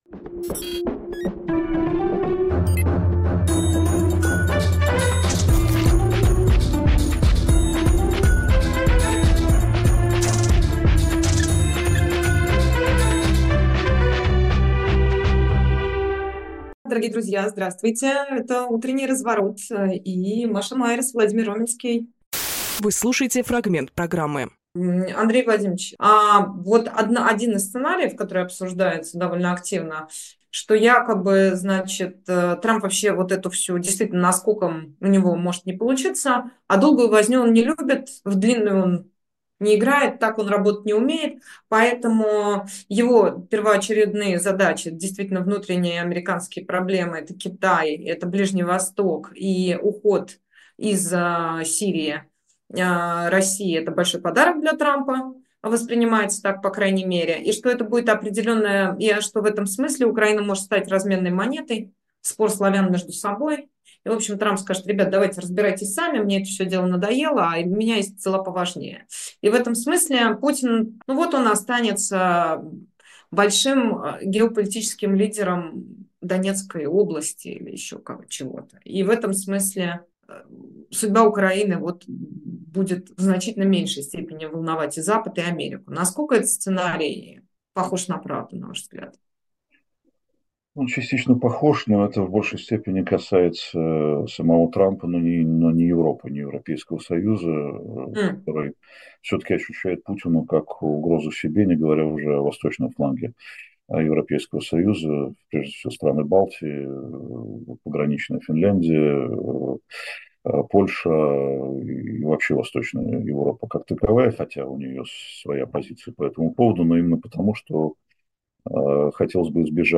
Фрагмент эфира от 16.12